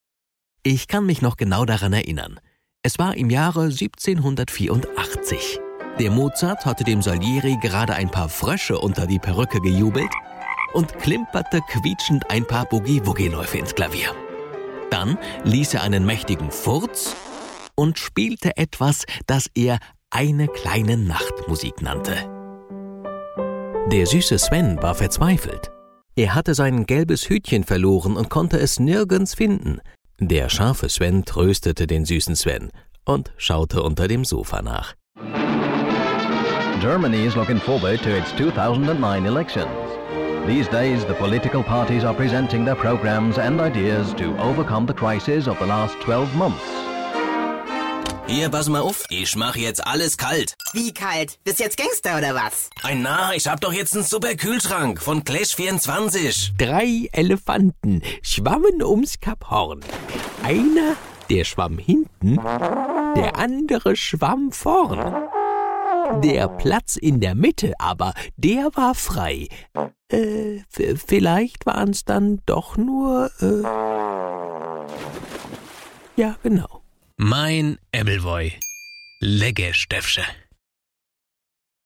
Studioausstattung: Desone/Soundblocker Kabine, Neumann TLM 103, Gefell M930, UA LA-610 Vollröhrenpreamp, GAP pre-73 MKII, RME Fireface, Genelec, Musiktaxi
Freundlich verbindliche Stimme.
Sprechprobe: Sonstiges (Muttersprache):